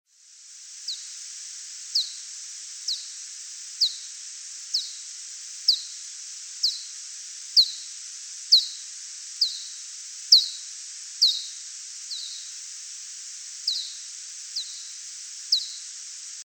Little Brown Bat
Bats use echolocation—short, ultrasonic calls that humans cannot hear—to detect prey.
Vocalizations are few but include "honking" which in one context may aid in preventing mid-air collisions among foraging adults.
The young produce isolation calls which aid in mother-young recognition.
little-brown-bat-call1.mp3